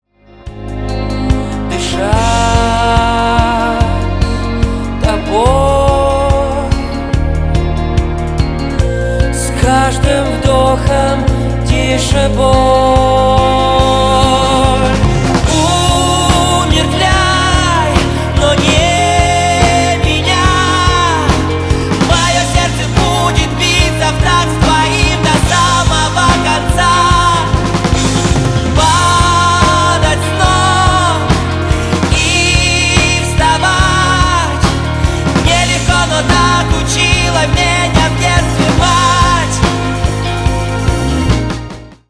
• Качество: 320, Stereo
мужской вокал
саундтреки
украинский рок
indie rock